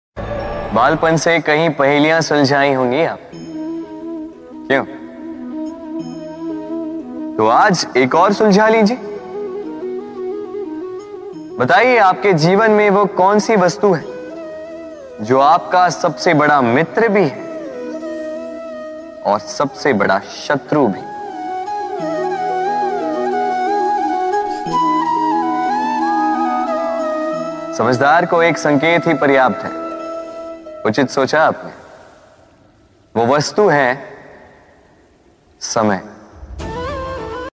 divine chants